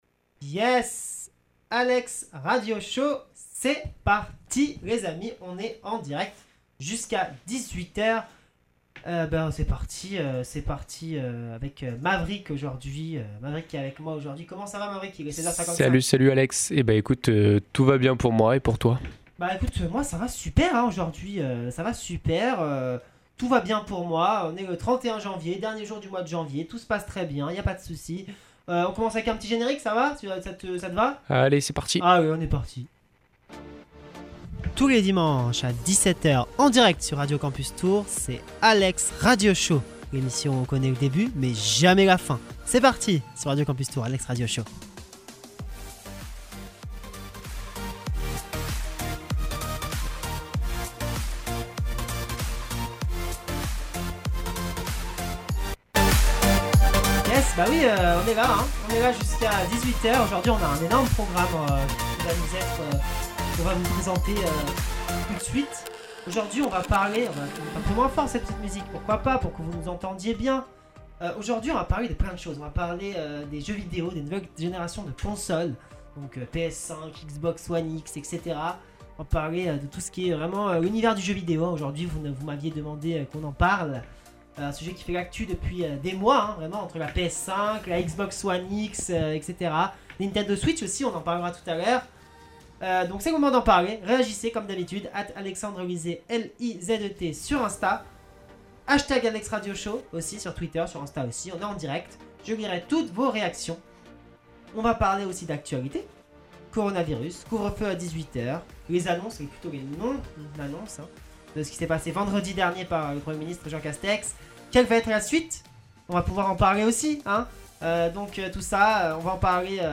Des musiques viendront rythmer le show toutes les 8 à 10 minutes et nous retrouverons la météo en début d’émission et le traditionnel horoscope en milieu d’émission.